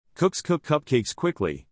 Este corto trabalenguas en inglés te ayudará con el sonido /k/, como en «coo, y el sonido /kw/ en «quickly».